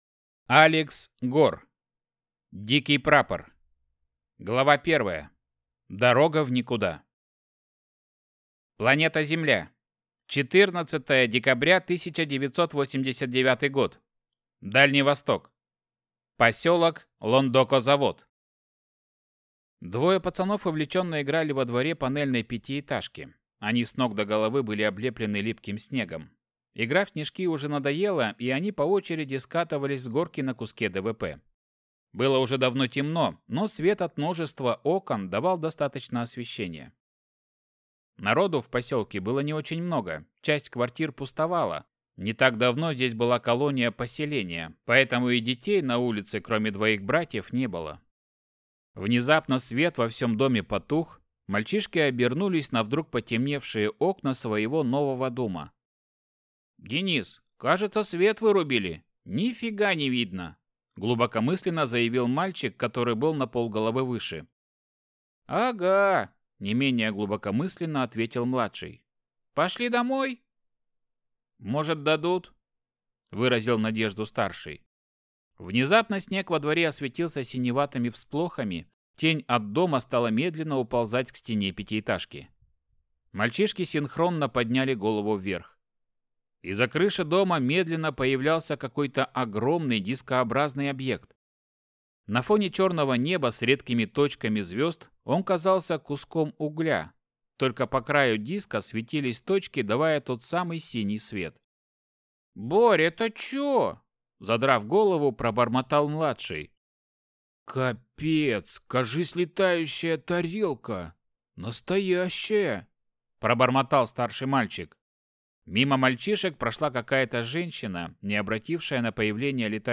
Аудиокнига Дикий прапор | Библиотека аудиокниг
Прослушать и бесплатно скачать фрагмент аудиокниги